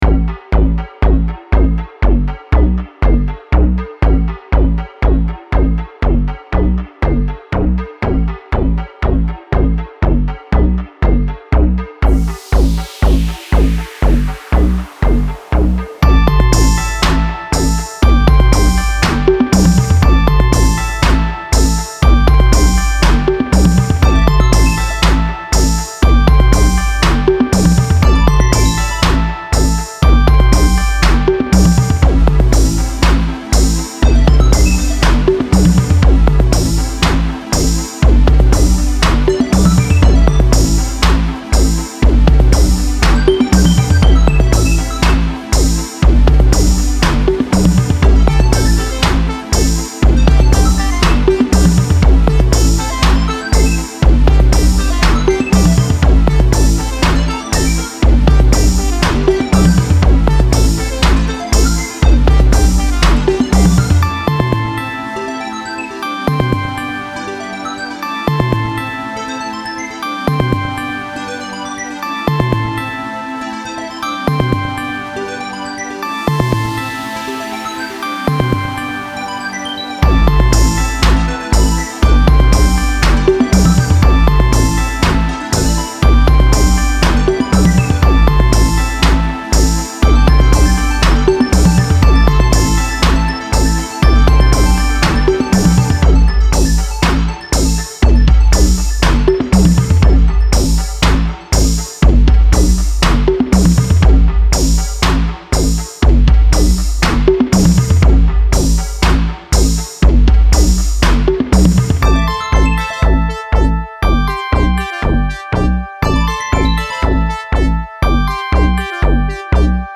Style Style EDM/Electronic, Oldies
Mood Mood Bouncy, Relaxed
Featured Featured Drums, Synth
BPM BPM 120